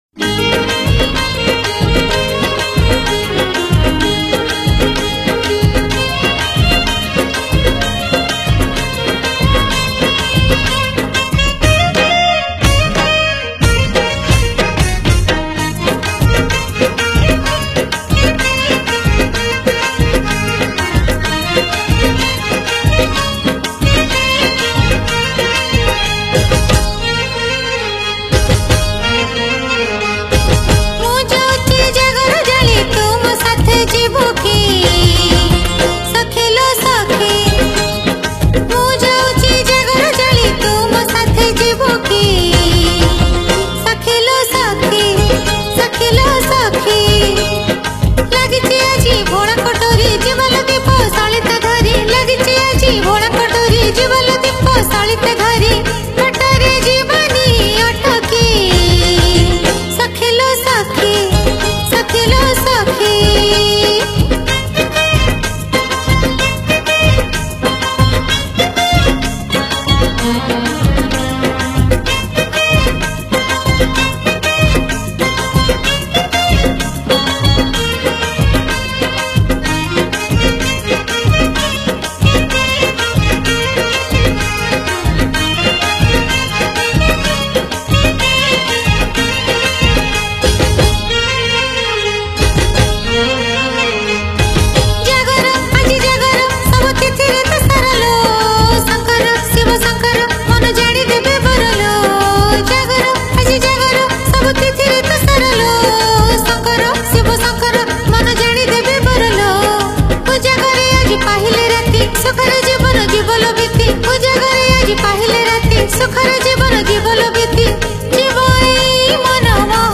Jagara Special Odia Bhajan Song